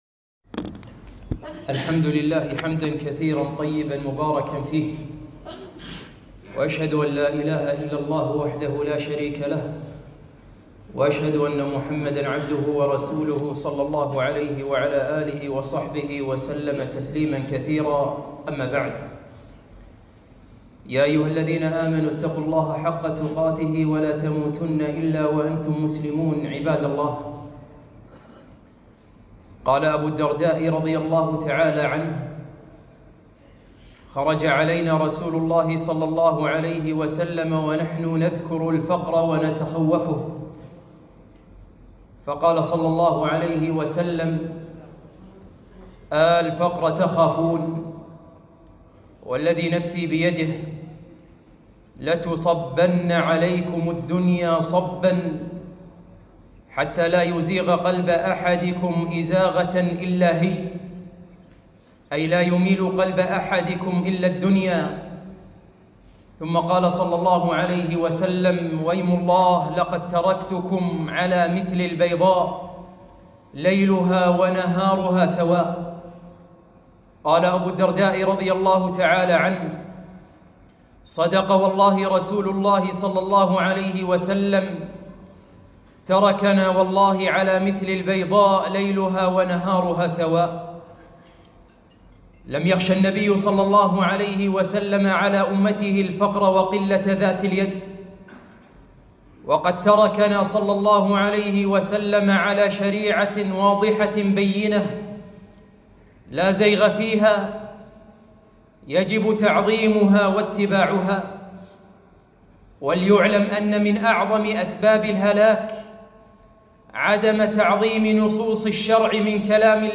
خطبة - مهلا أيها العقلانيون !